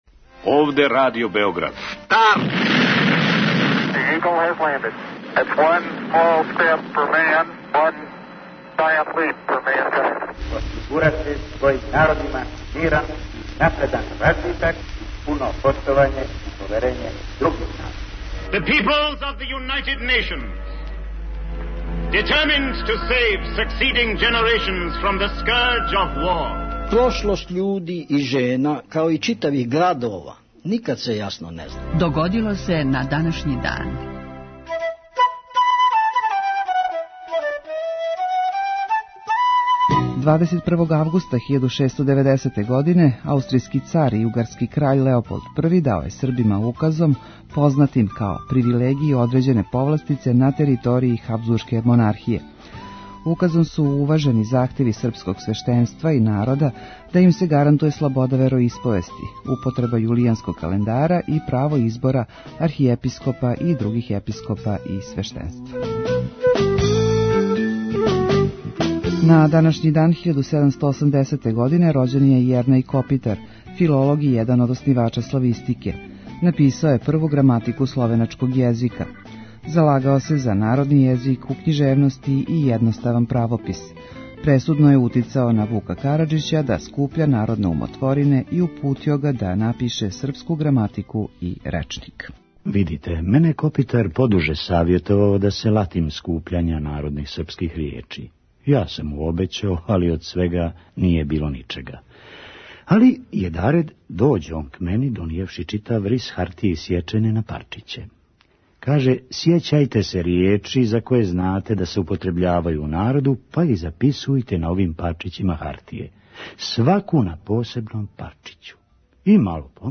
У 5-томинутном прегледу, враћамо се у прошлост и слушамо гласове људи из других епоха.